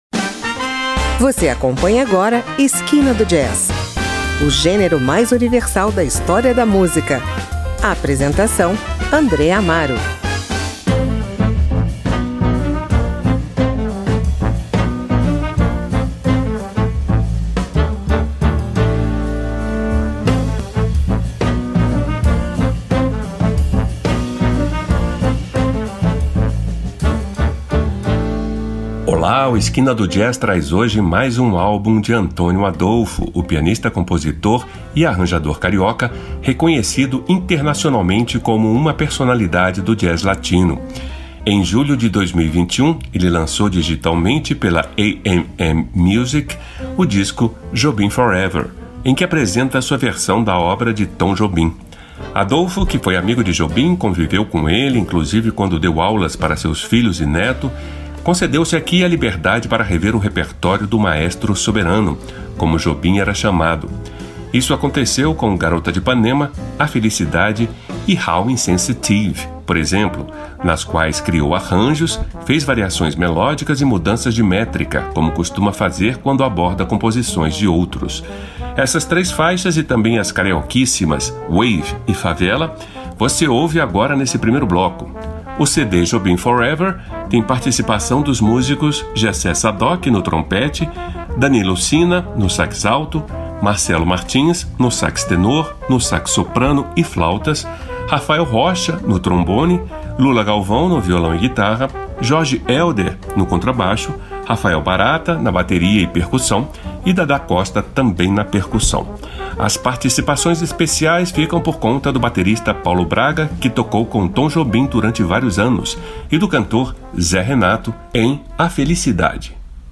Mais um álbum do pianista
jazz latino